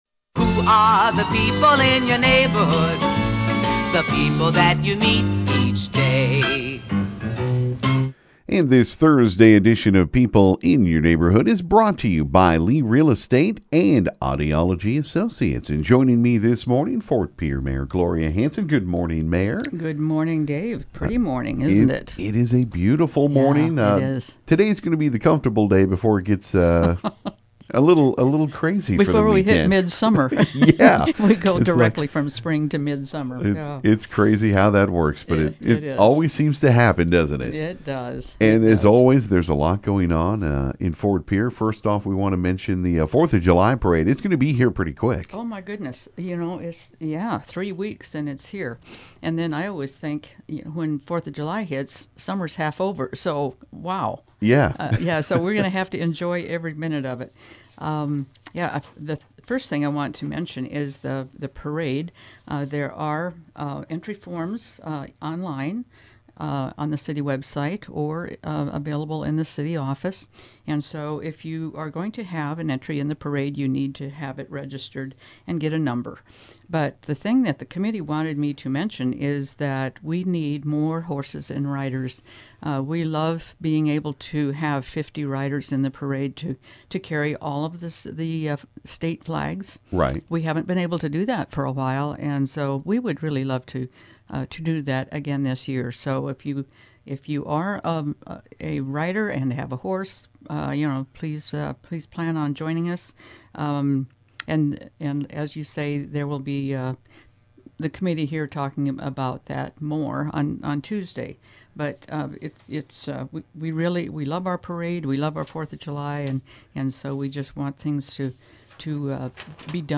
This morning Ft. Pierre Mayor Gloria Hanson visited KGFX